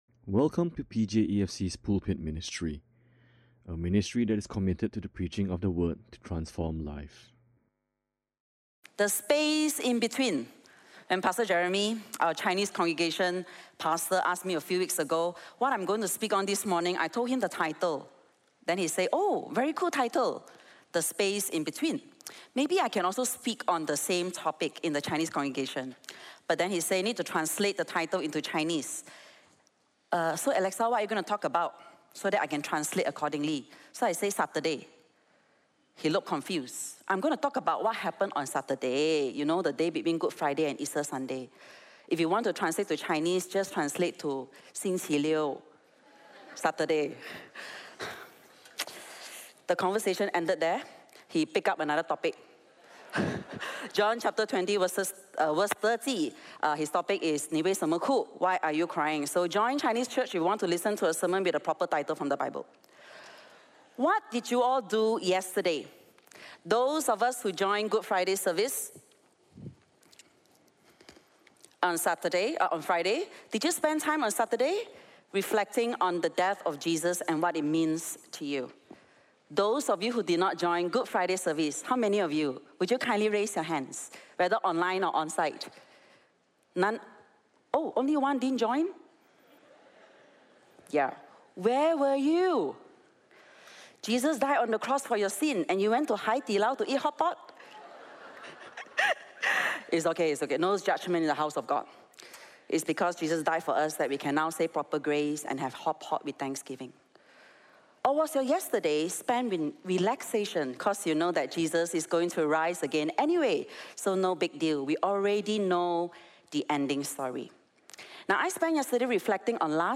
Listen to Sermon Only
This sermon is in conjunction with Easter Sunday.